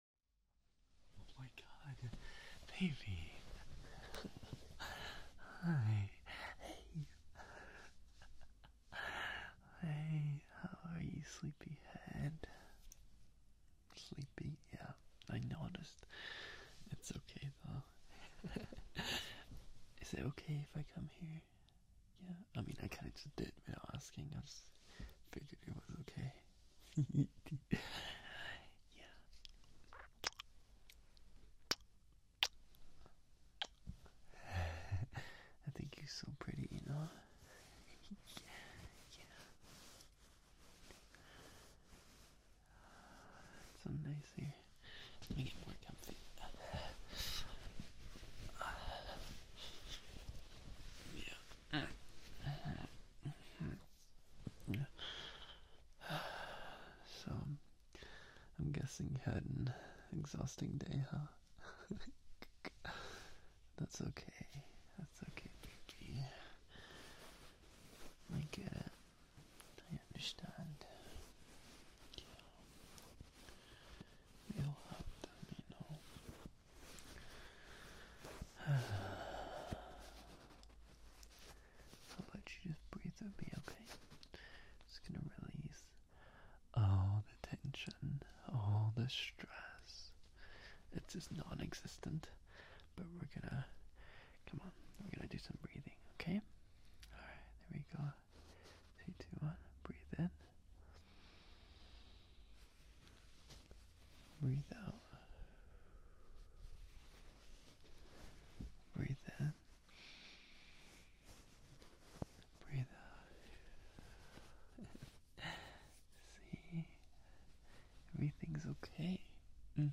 playing with your face, binaural